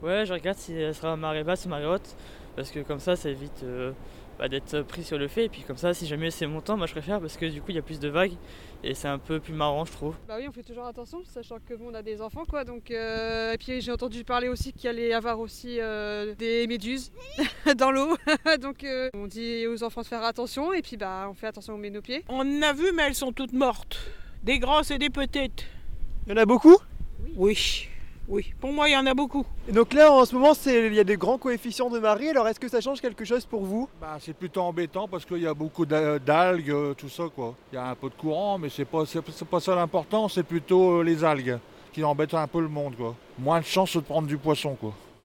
Grandes marées et méduses : les réactions des habitants et des touristes sur la plage.